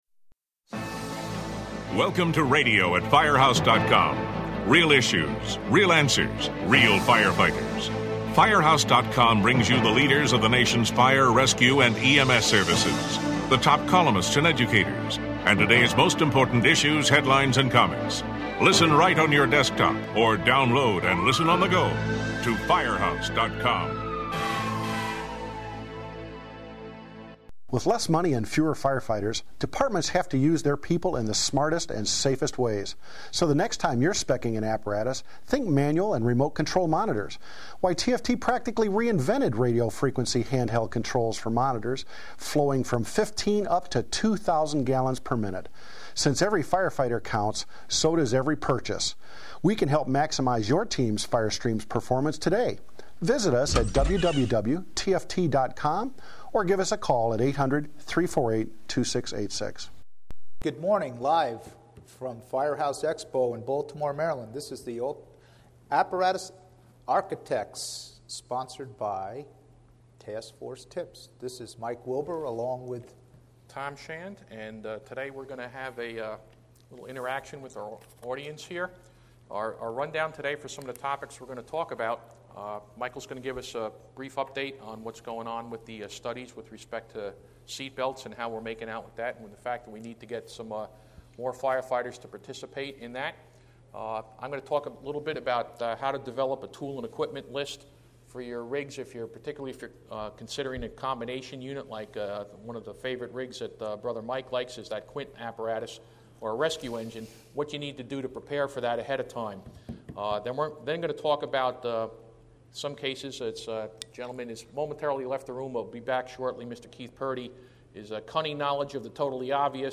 The Apparatus Architects: Live from Firehouse Expo
The Apparatus Architects come to you from the classroom at Firehouse Expo where several hot topics were discussed.